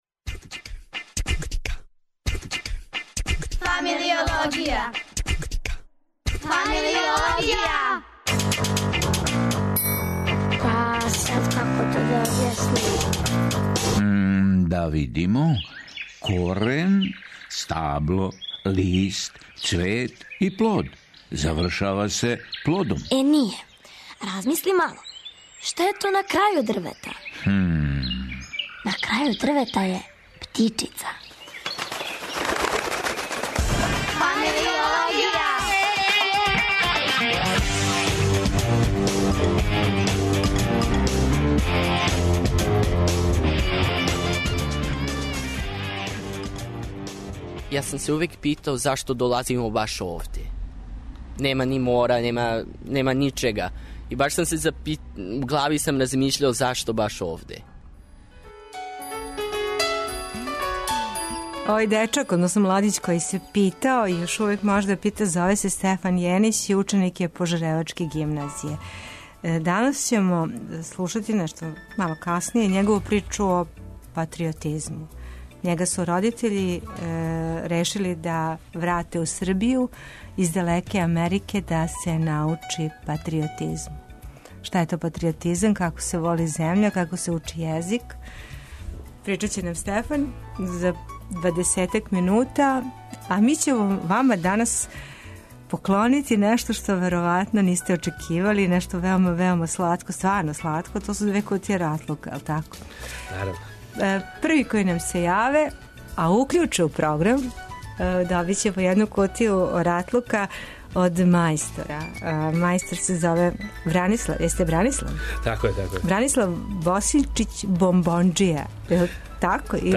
Дечји цртеж увек је изнова занимљива тема, данас је у Пиратима... Гост у студију